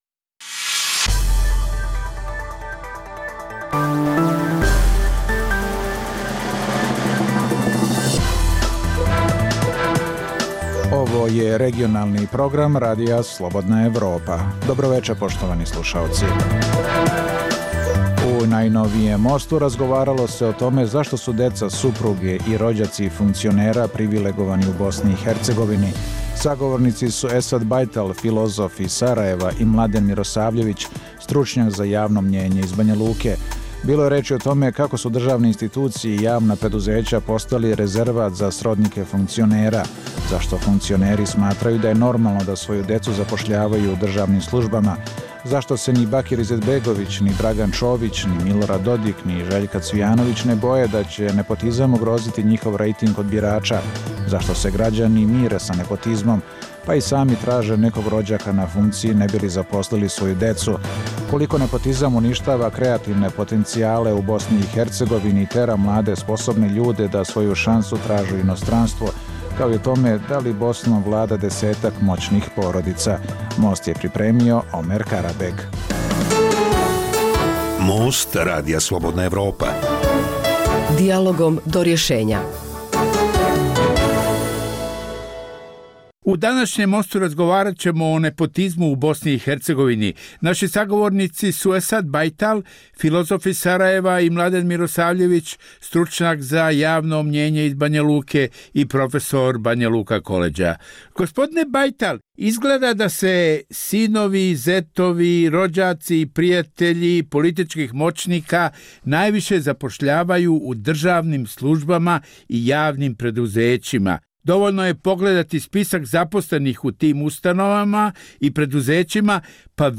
u kojem ugledni sagovornici iz regiona razmtraju aktuelne teme. U najnovijem Mostu razgovaralo se o tome zašto su deca, supruge i rođaci funkcionera privilegovani u Bosni i Hercegovini.